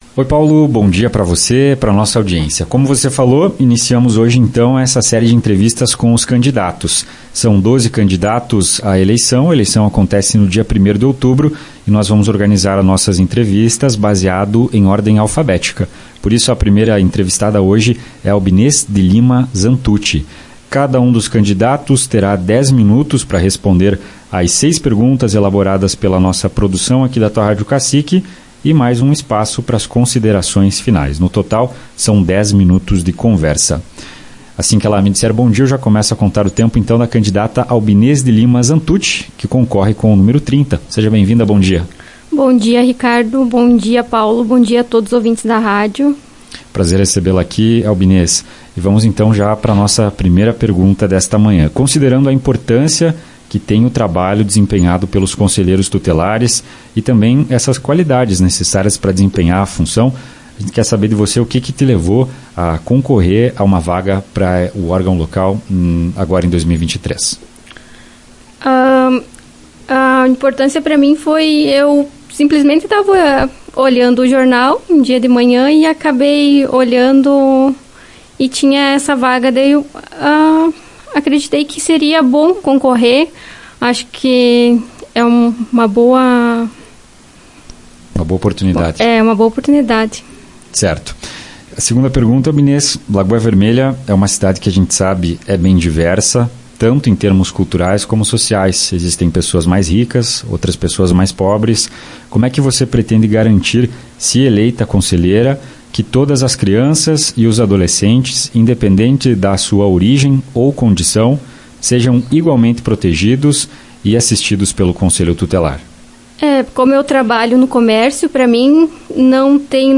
Tua Rádio realiza entrevistas com candidatos às vagas no Conselho local